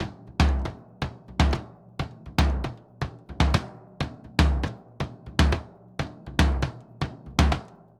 Bombo_Candombe_120_2.wav